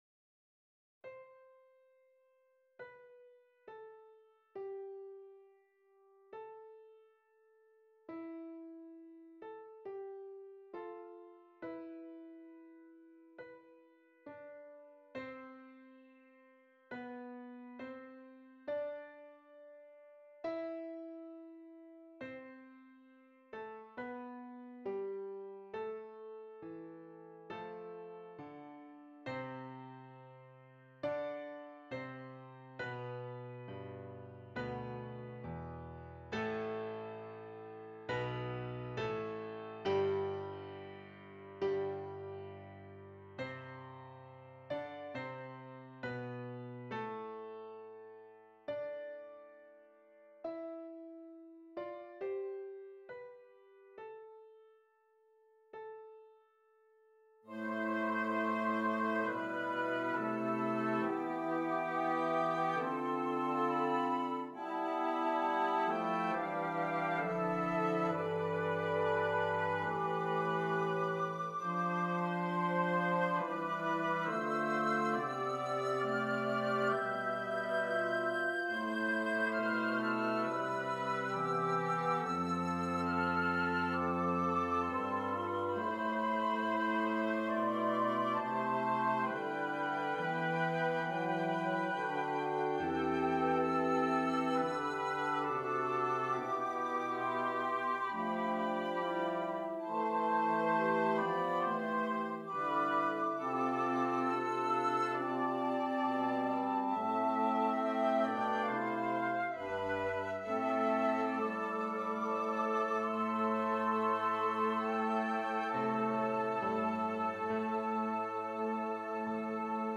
Woodwind Quinte and Piano
for woodwind quintet and piano
a colorful montage for winds and piano